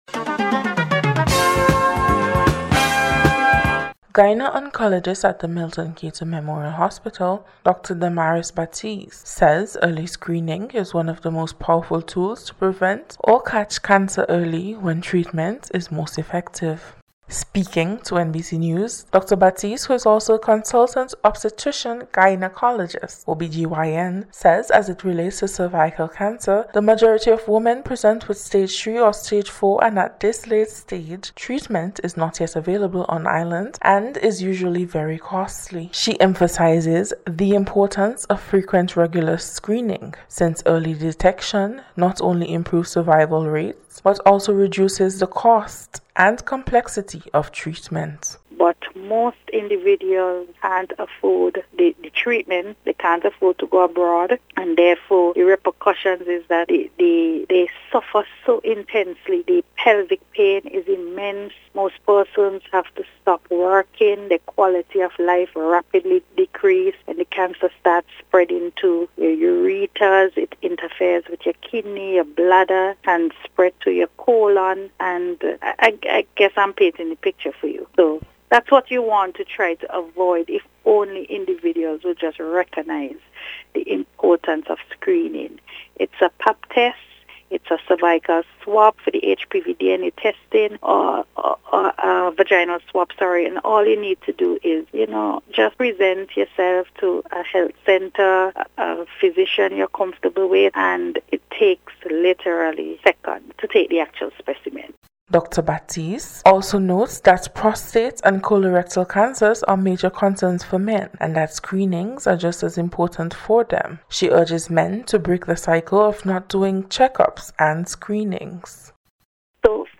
NBC’s Special Report- Wednesday 22nd January,2025